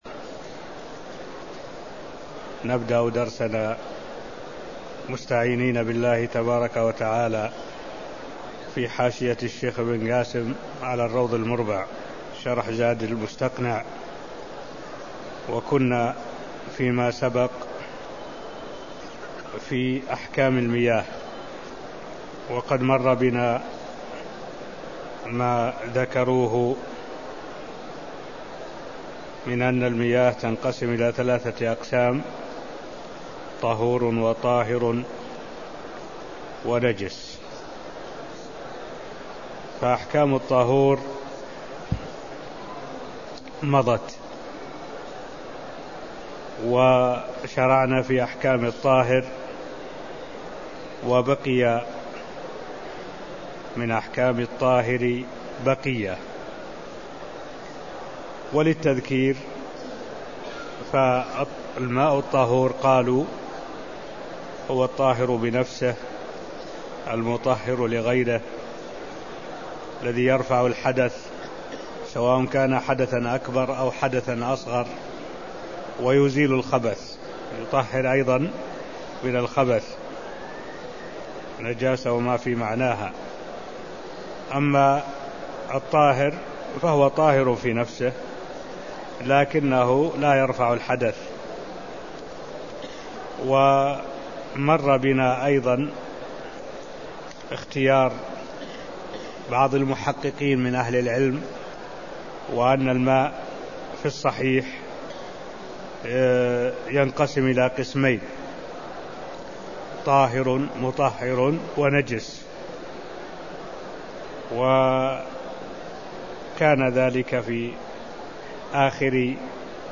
المكان: المسجد النبوي الشيخ: معالي الشيخ الدكتور صالح بن عبد الله العبود معالي الشيخ الدكتور صالح بن عبد الله العبود القسم الثالث من اقسام المياه (0015)